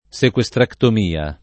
vai all'elenco alfabetico delle voci ingrandisci il carattere 100% rimpicciolisci il carattere stampa invia tramite posta elettronica codividi su Facebook sequestrectomia [ S ek U e S trektom & a ] (meglio che sequestrotomia ) s. f. (med.)